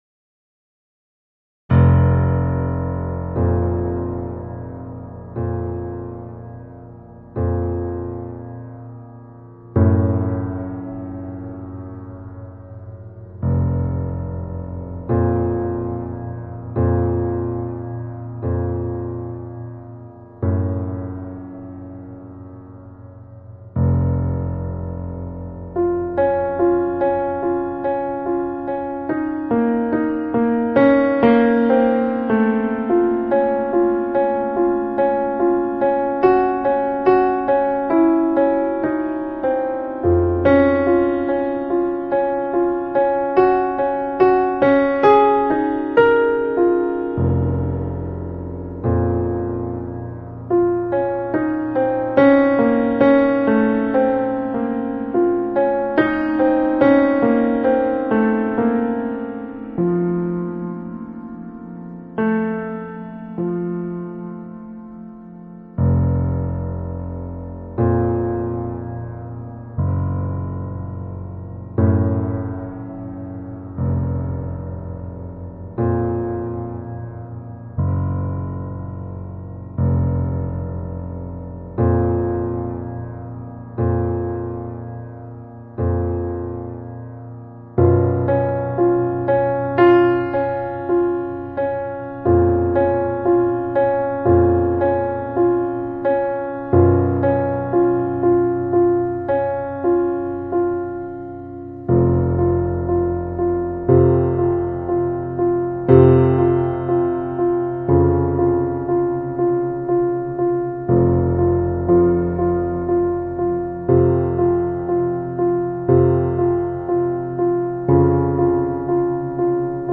Art and Structure: Music: sonata sketch excerpt
Having little time to compose amongst many other projects, a piano sonata seemed a manageable work to undertake as a first complete piece in place of several larger orchestral ideas.
This material may resurface in some later work, and does feature perhaps my earliest use of an ostinato device which seems a favored choice of mine going forward.